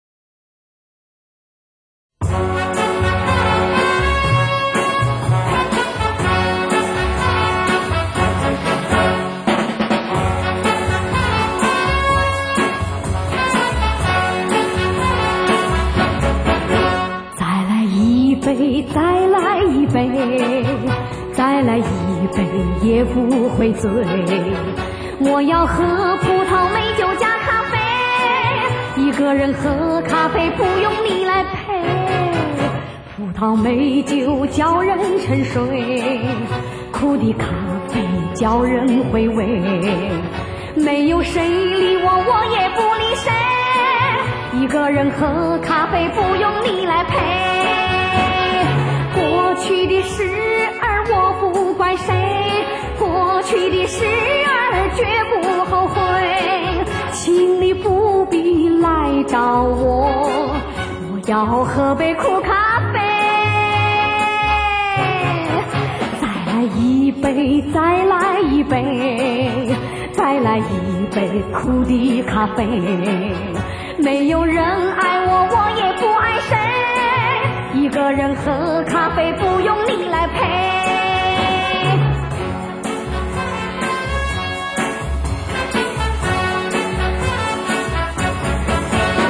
專輯類別：國語流行、絕版重現